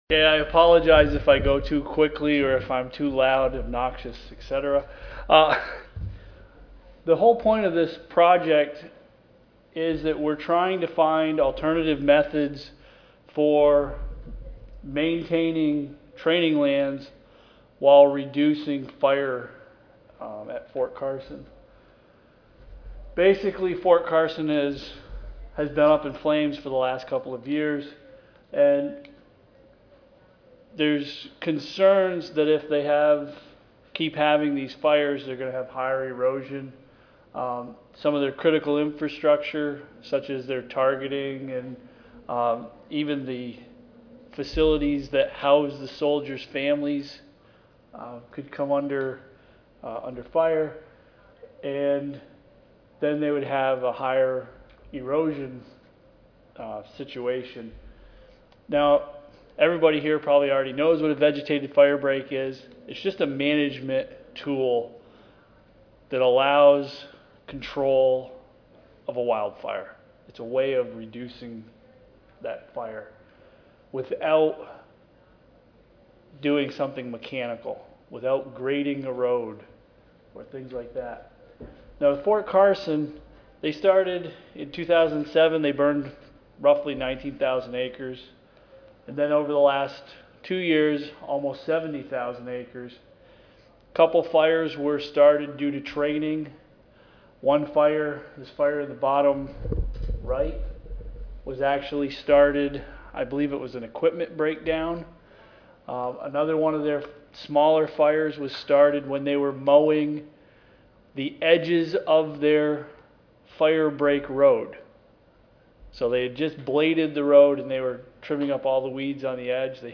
IL Audio File Recorded presentation Fort Carson has experienced a series of recent wildfires. ERDC was asked to help develop a study to evaluate vegetative firebreaks as a mechanism for wildfire control.